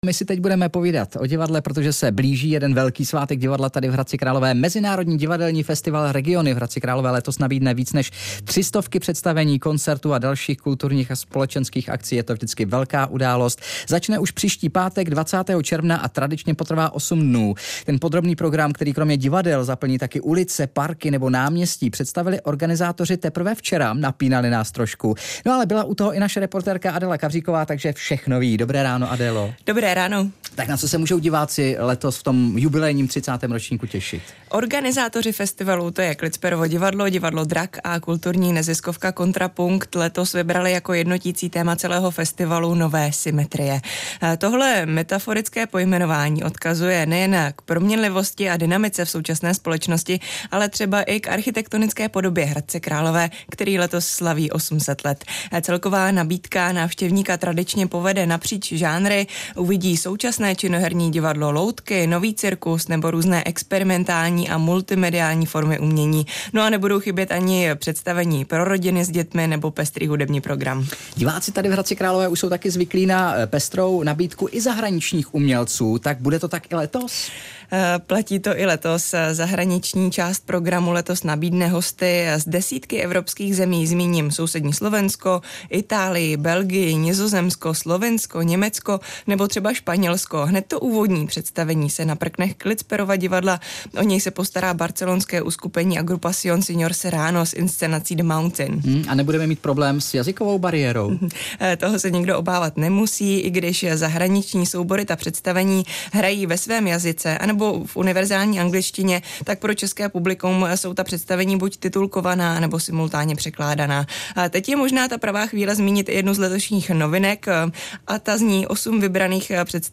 Zprávy pro Královéhradecký kraj: Jižní stěnu hradu Pecka na Jičínsku teď zakrývá mohutné lešení.